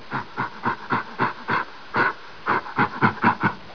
جلوه های صوتی
دانلود صدای حیوانات جنگلی 29 از ساعد نیوز با لینک مستقیم و کیفیت بالا